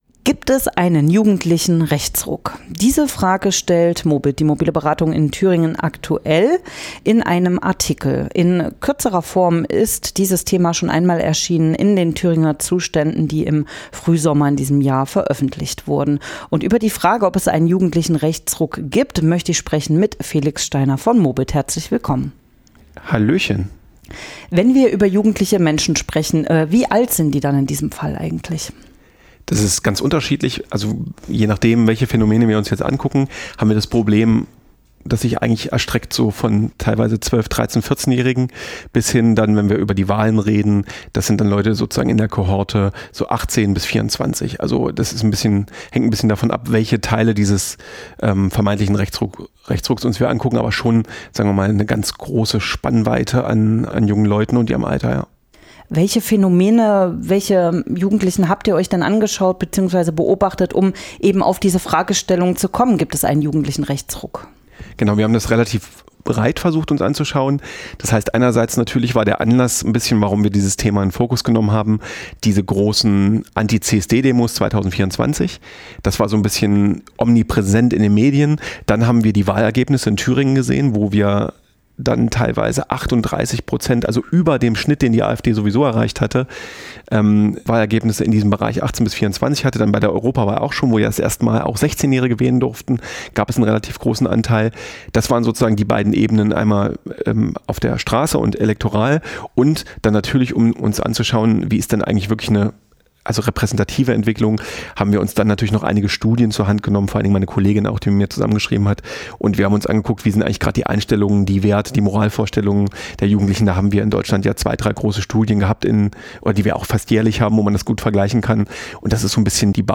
Gibt es einen jugendlichen Rechtsruck? | Interview mit Mobit
Interview_JugendlicherRechtsruck.mp3